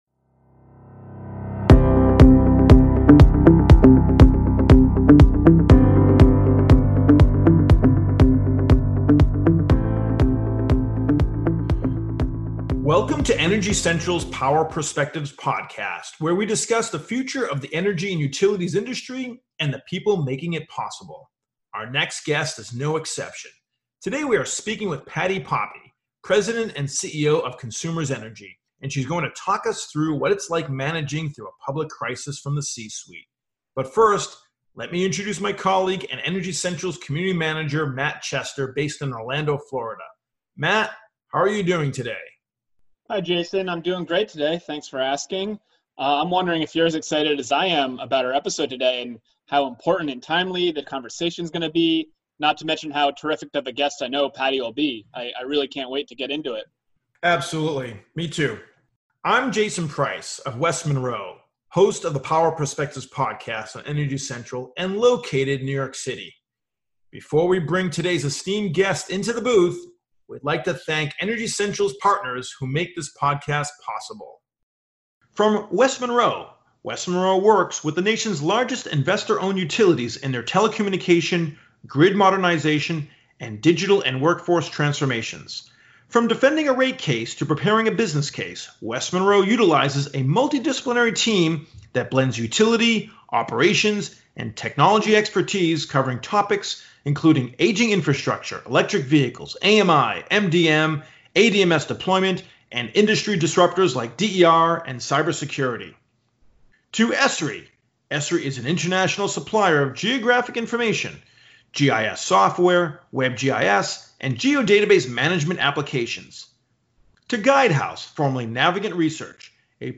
Patti Poppe, the CEO of Consumers Energy, found herself in that role of leading her company through the unknown, and on today’s episode of the Power Perspectives Podcast she shares some of her lessons learned.